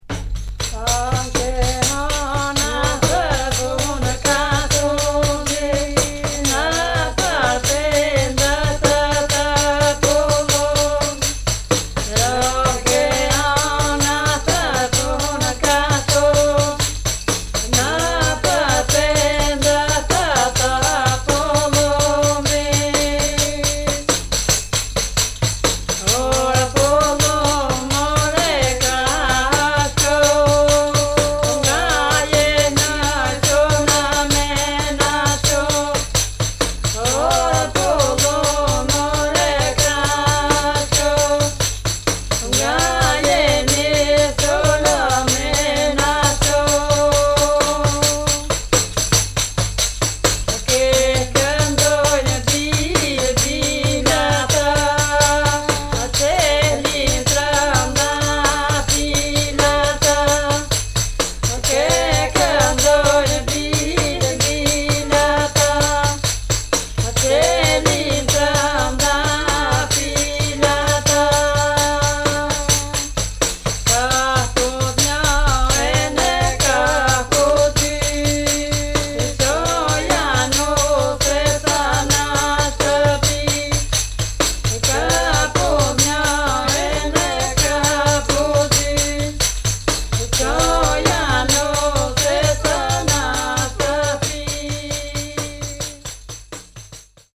旧ユーゴスラヴィアのマケドニアで録音されたアルバニア人の音楽。
特異な多声音楽文化を持つことで知られるアルバニア人の中でも、異郷風情まんてんのポリフォニーを奏でるトスク人の音楽にフォーカス。
女声による不協和音の輪舞は村の結婚式での一幕。
キーワード：Ocora　現地録り　ドローン